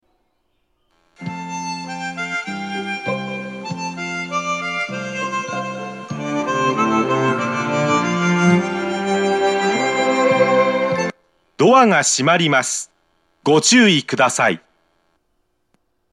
発車メロディー
停車時間に対して曲が長すぎるので、フルコーラスはまず鳴りません。